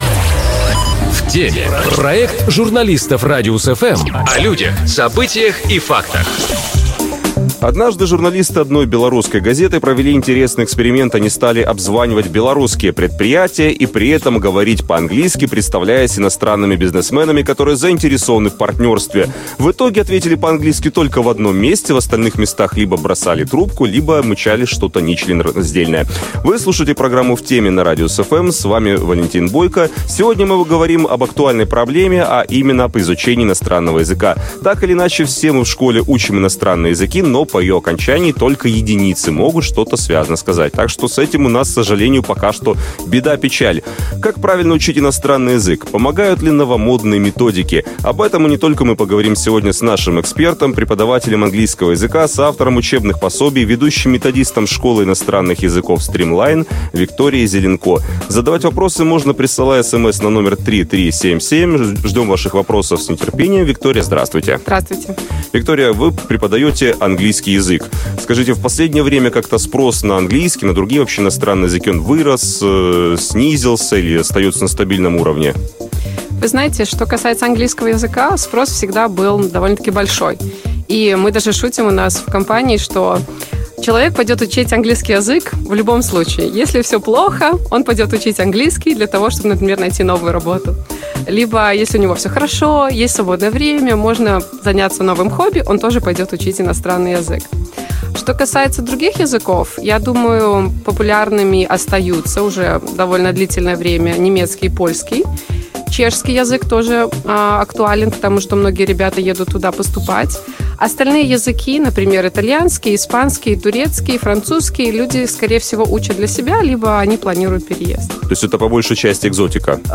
Об этом и не только - в разговоре с нашей гостьей